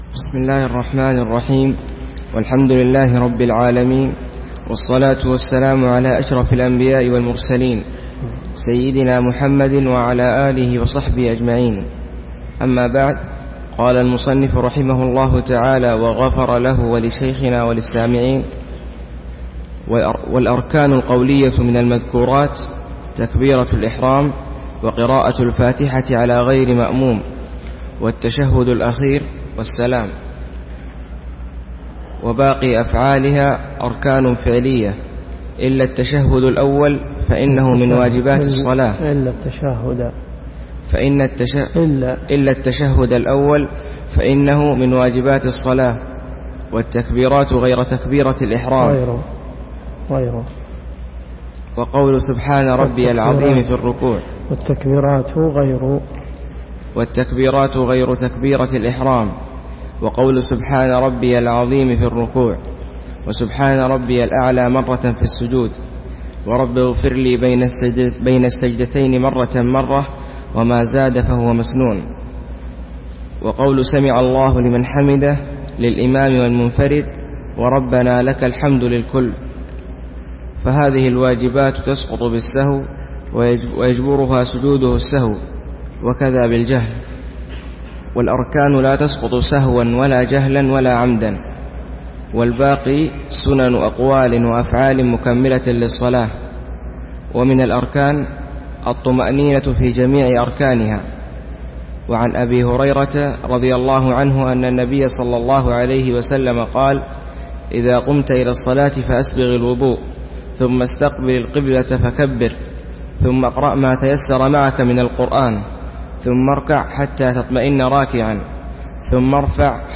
الرئيسية الدورات الشرعية [ قسم الفقه ] > منهج السالكين . 1428 + 1429 .